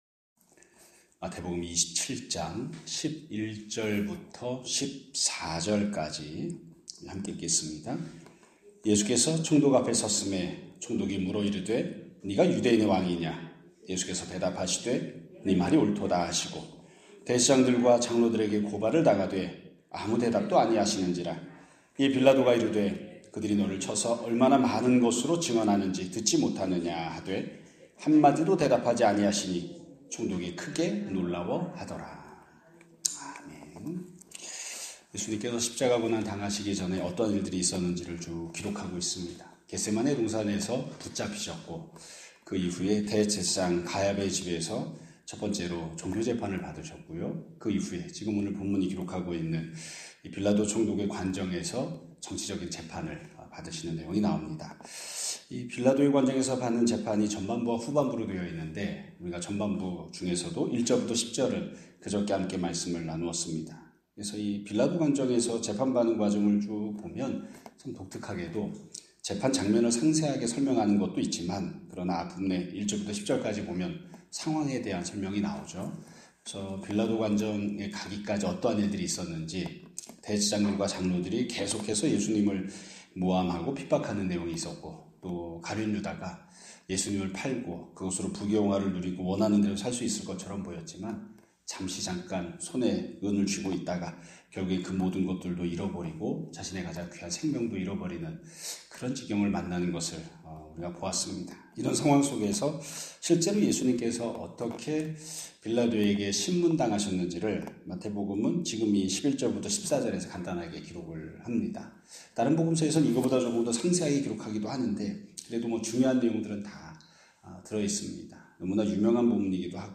2026년 4월 15일 (수요일) <아침예배> 설교입니다.